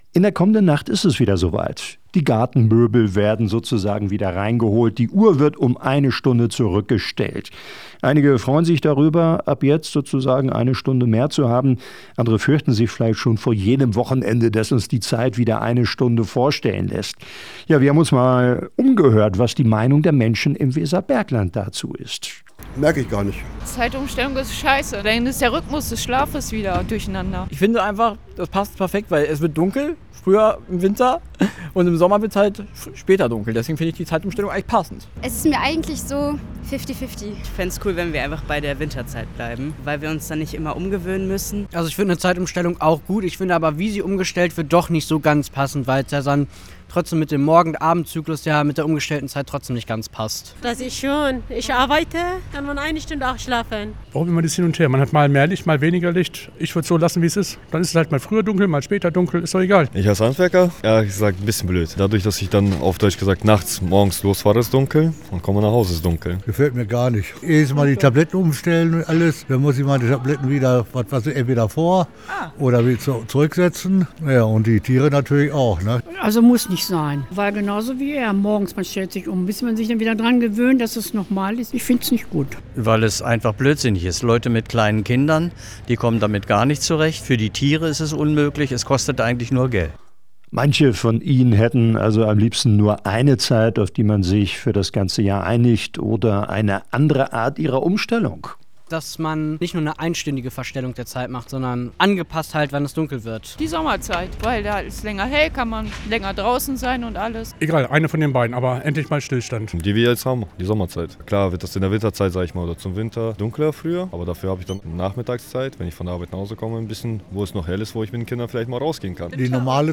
Hameln-Pyrmont: Umfrage: Was halten Sie von der Zeitumstellung?
hameln-pyrmont-umfrage-was-halten-sie-von-der-zeitumstellung.mp3